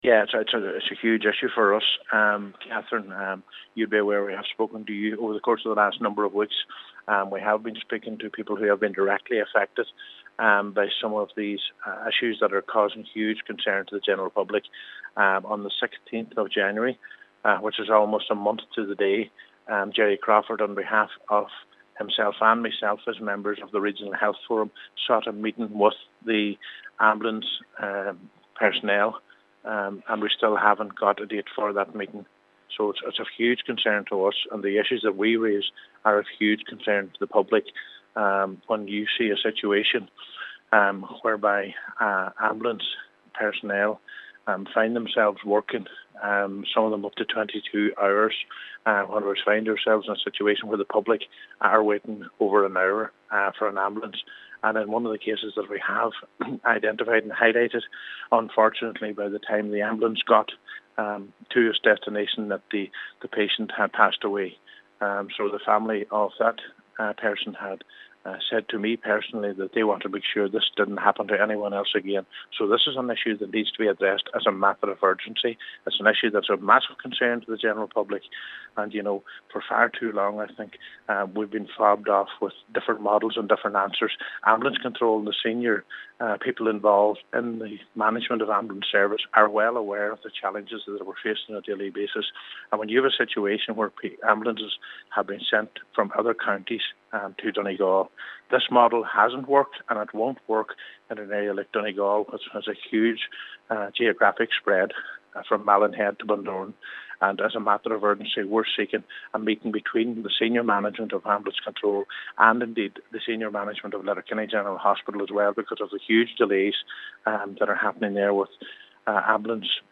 Cllr. Ciaran Brogan, a member of the Regional Health Forum has requested an urgent meeting in a bid to address the crisis: